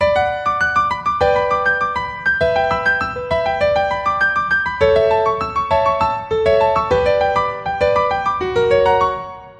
原声钢琴 11100bpm
描述：A小调HipHop/Rap钢琴循环播放...